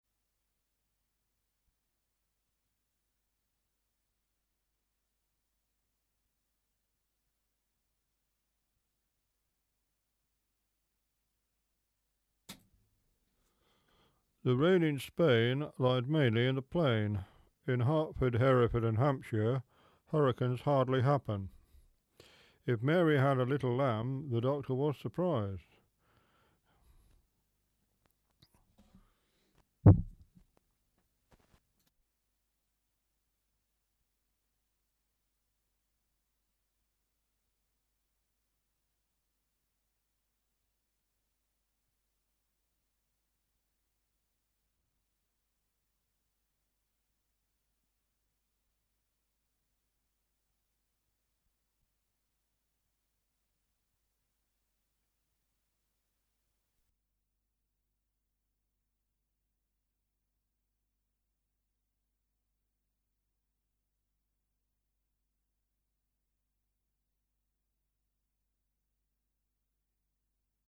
The mic is a Prodipe TT1 with big foam gag on it and I am brushing it with lips.
Mic into unbalanced recorder input via adaptor (picks up hum a treat!) .
Before the tape moves the replay amp noise is -56dBu and the tape noise makes that worse to -50dB.
Tape noise was -51dBFS and, big thump, "OFF" noise dropped to -63dBFS and is mainly 50Hz hum from the 4mtr unbalanced lead draped across the room.